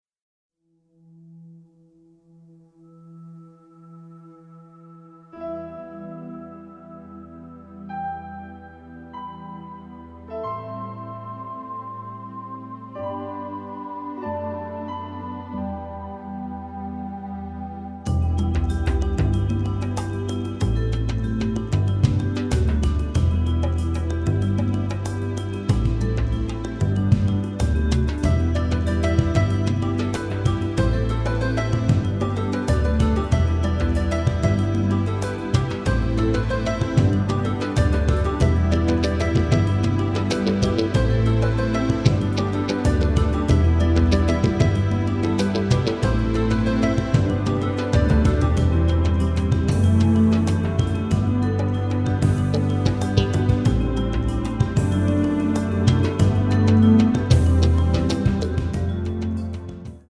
Buoyant, dramatic and heartfelt